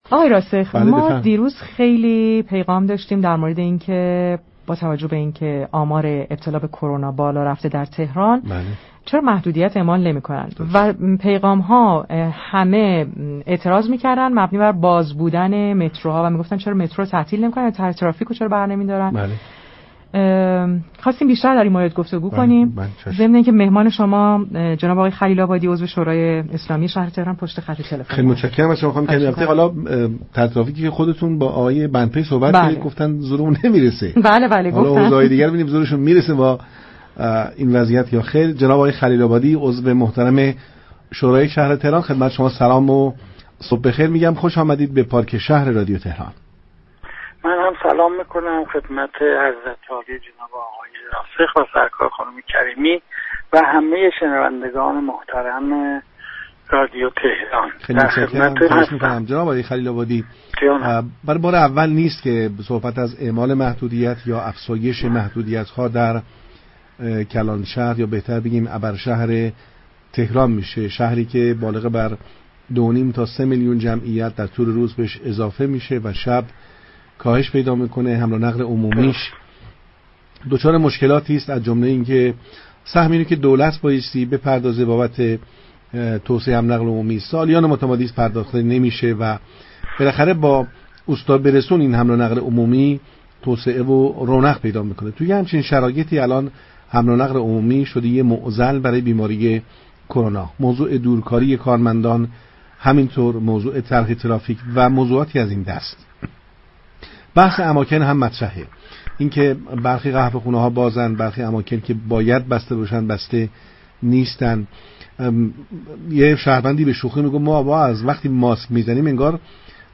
حسن خلیل آبادی، عضو شورای شهر تهران در رابطه با اعمال محدودیت ها ؛ اعتراض مردم در بازبودن مترو عدم لغو طرح ترافیك با پارك شهر رادیو تهران گفتگو كرد.